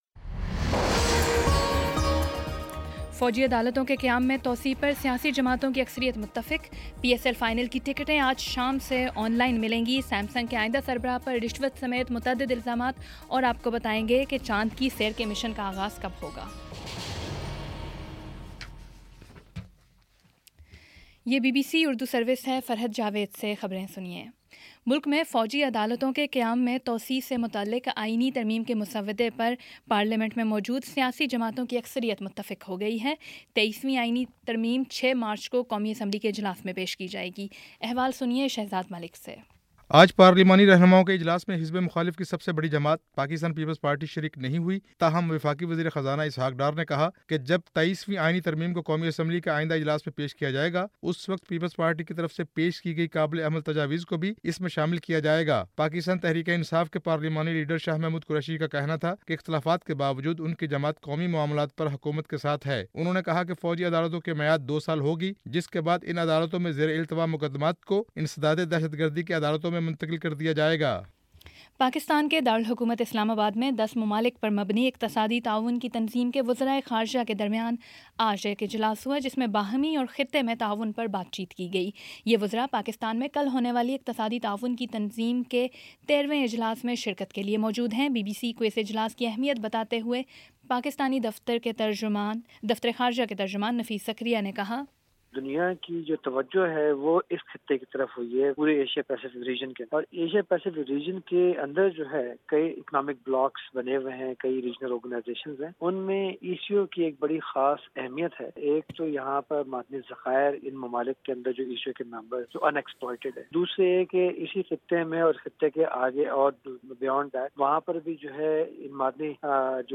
فروری 28 : شام چھ بجے کا نیوز بُلیٹن